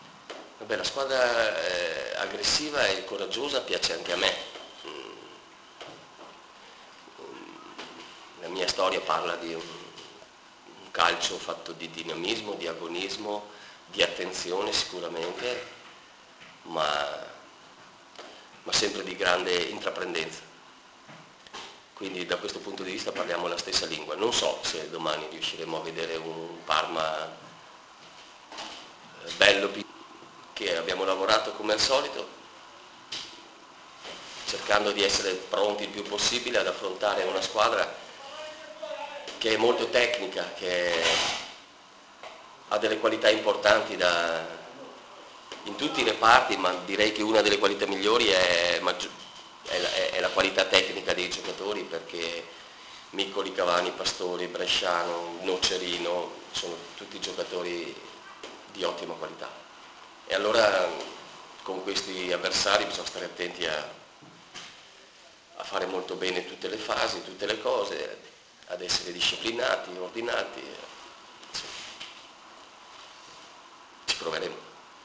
Parma – Palermo: Guidolin sfida il suo passato. Estratto audio della conferenza stampa